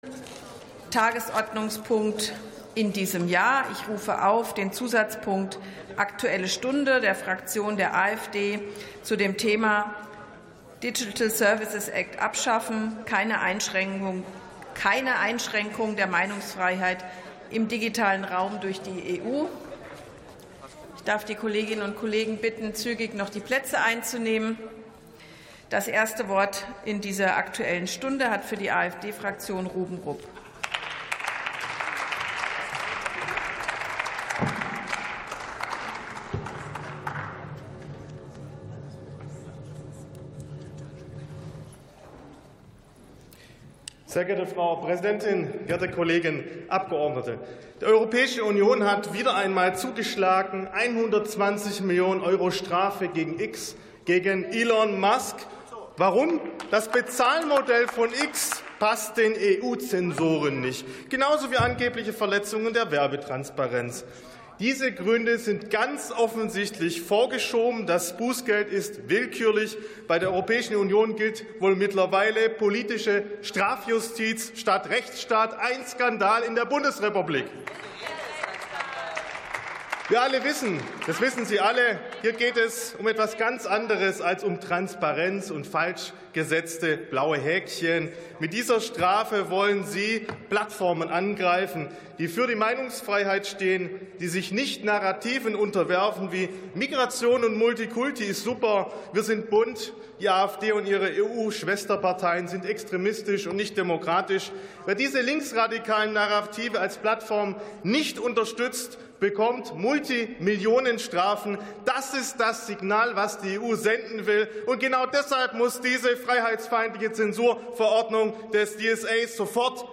51. Sitzung vom 19.12.2025. TOP ZP 8: Aktuelle Stunde zum Digital Services Act ~ Plenarsitzungen - Audio Podcasts Podcast